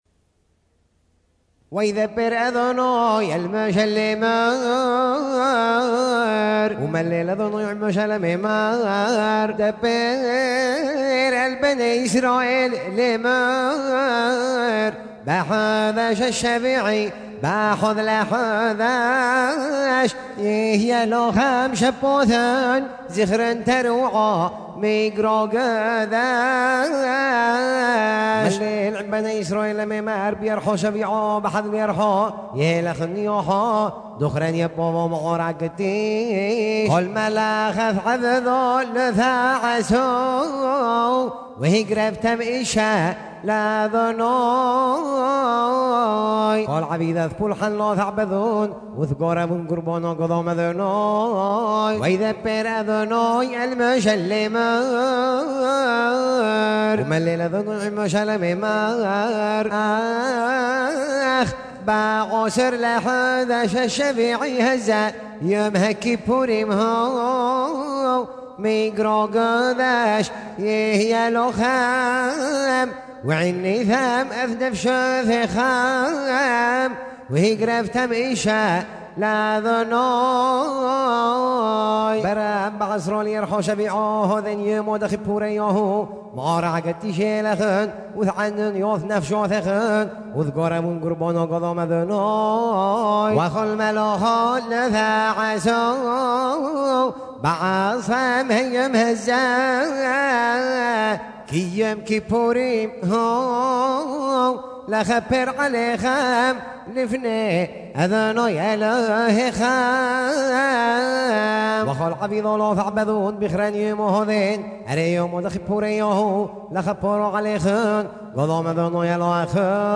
ז'אנר: Blues.